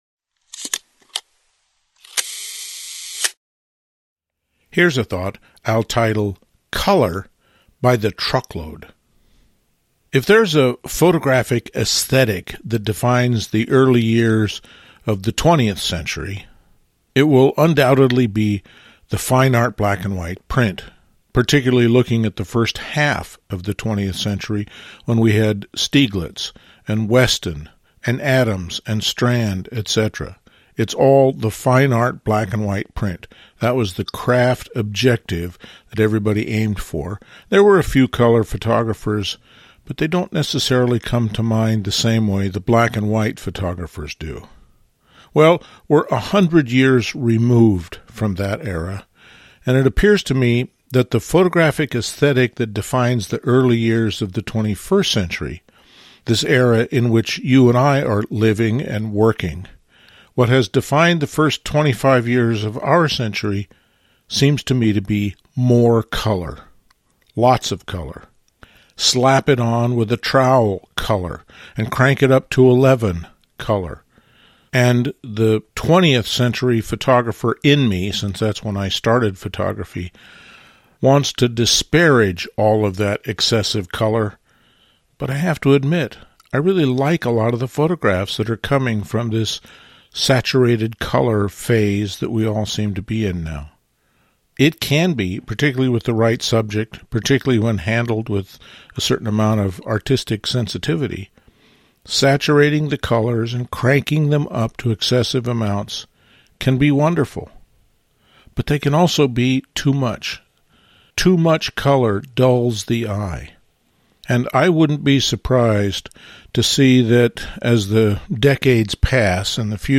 Included in this RSS Feed are the LensWork Podcasts — posted weekly, typically 10-20 minutes exploring a topic a bit more deeply — and our almost daily Here's a thought… audios (extracted from the videos.)